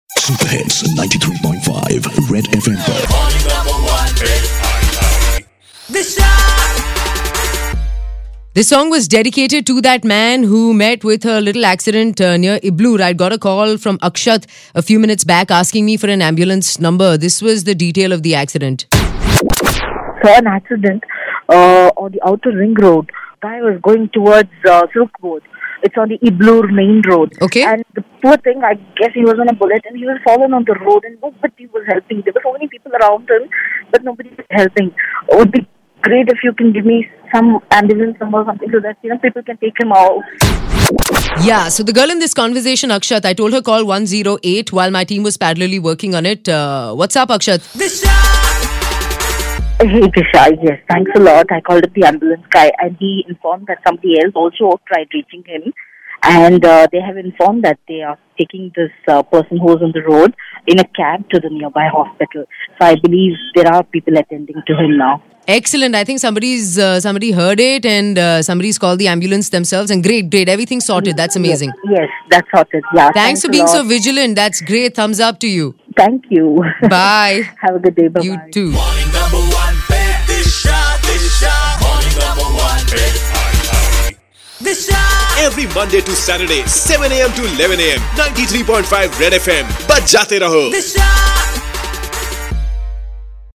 When a listener called up and confiirmed